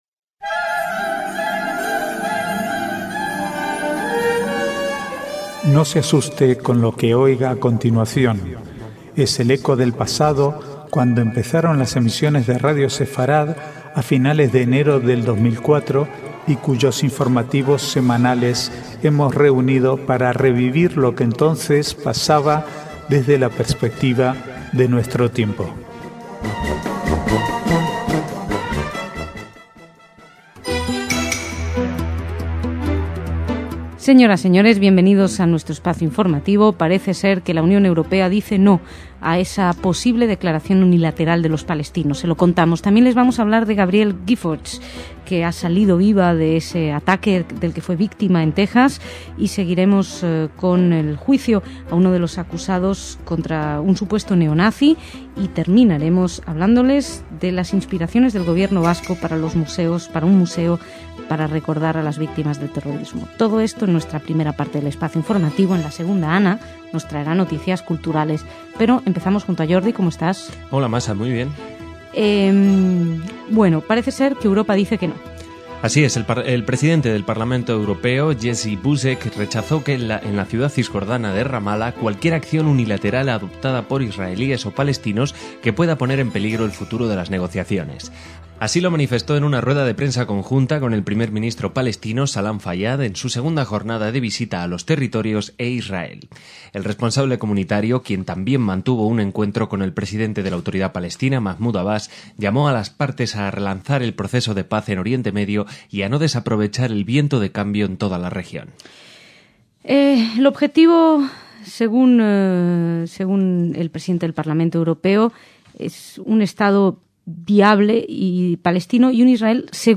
Archivo de noticias del 16 al 21/6/2011